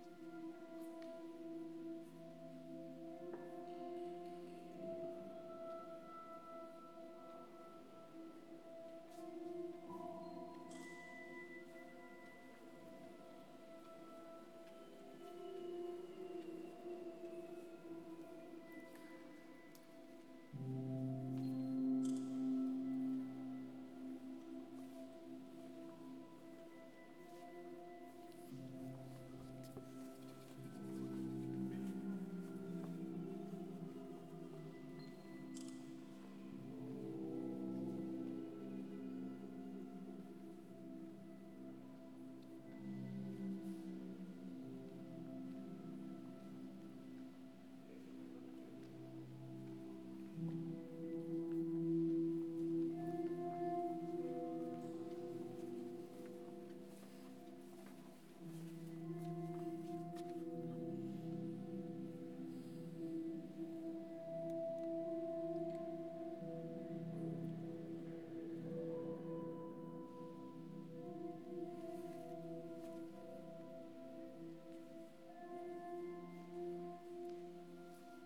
Empty-Chamber.ogg